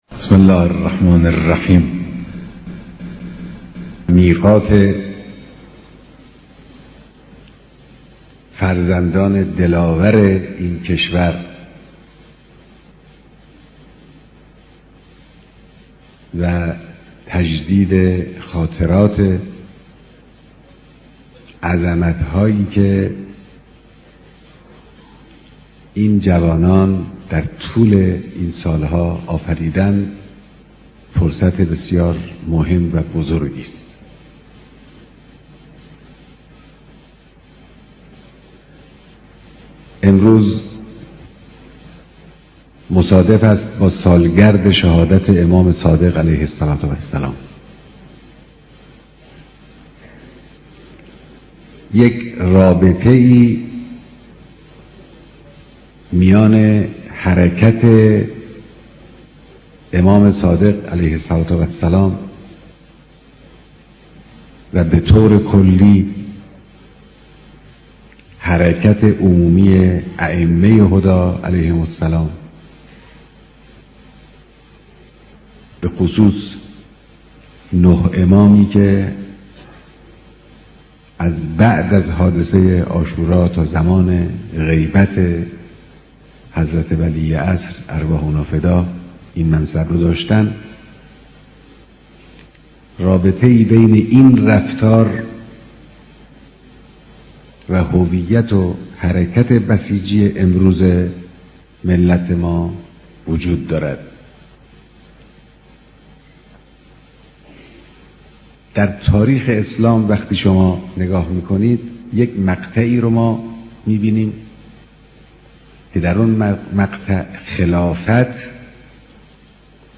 ديدار هزاران نفر از بسيجيان سراسر كشور